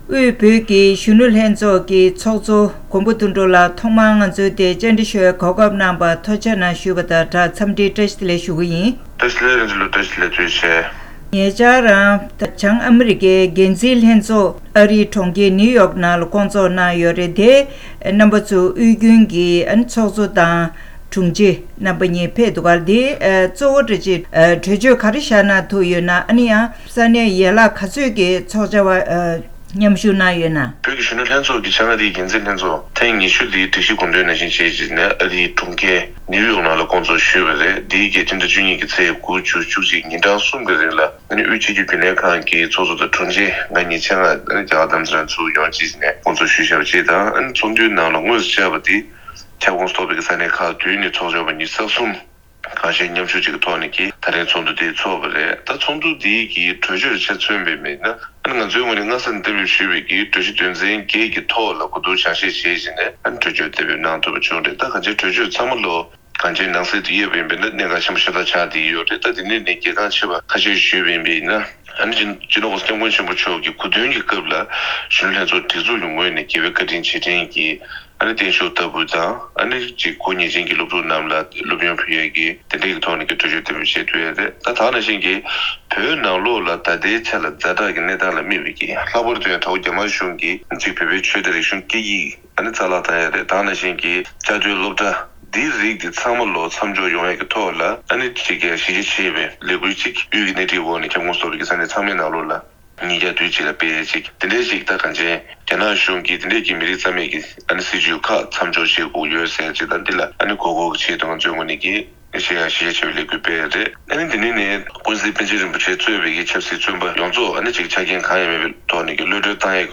དམིགས་བསལ་བཀའ་འདྲིའི་ལེ་ཚན་ནང་།